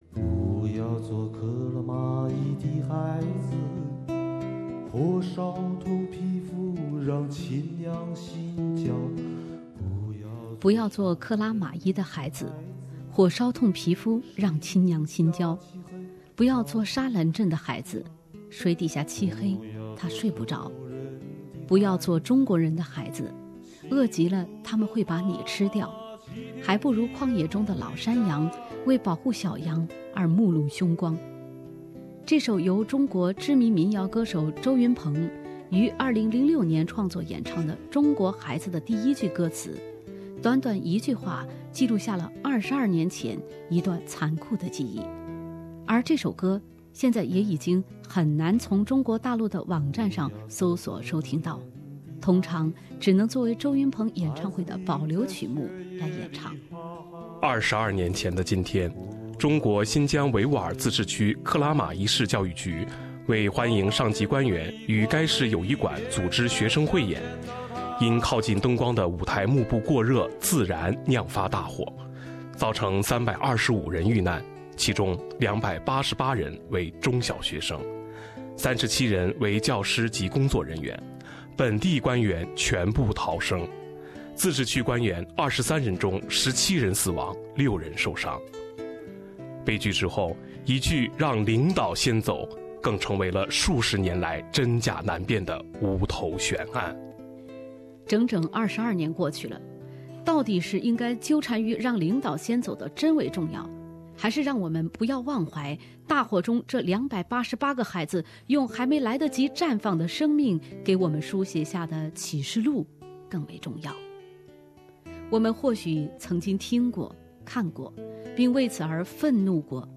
因此，在采访一开始，当记者刚提及克拉玛依大火，她立即就答出了准确的时间。这样的一份记住，在她看来，也是对 被克拉玛依大火吞噬的325个生命的一种怀念。